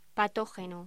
Locución: Patógeno
voz